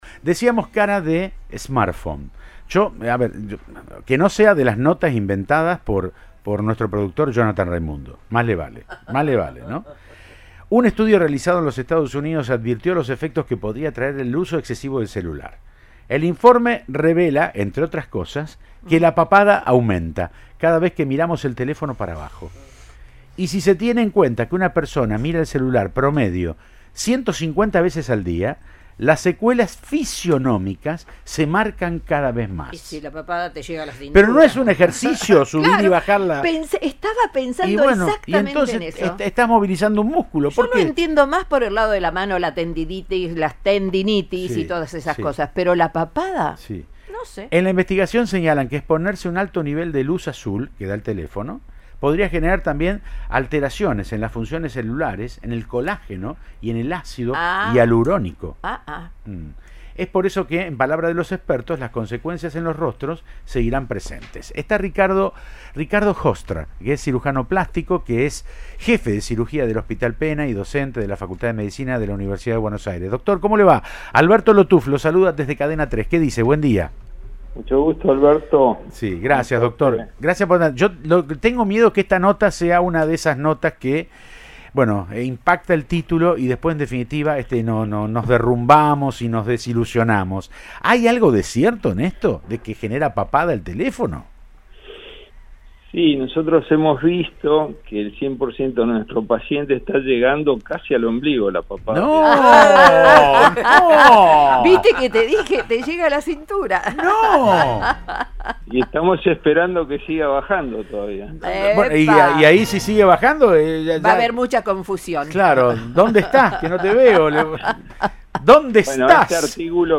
El cirujano plástico